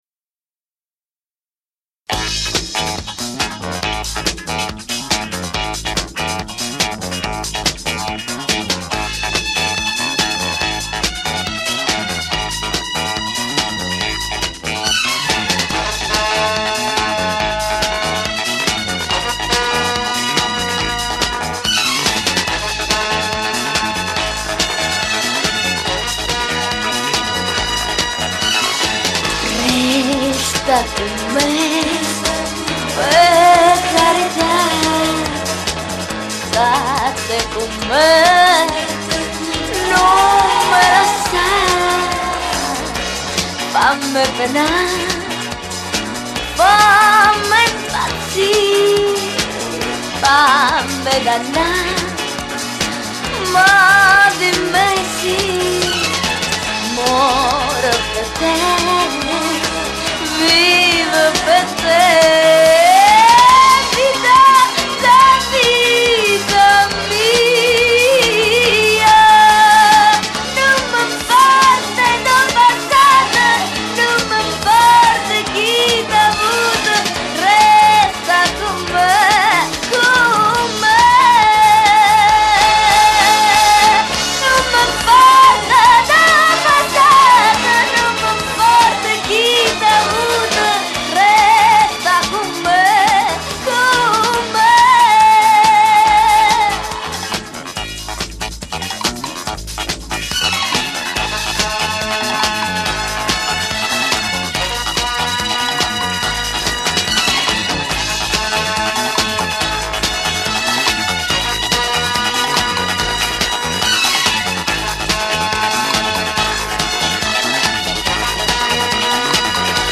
Basso
Batteria
Chitarra Acustica
Tastiere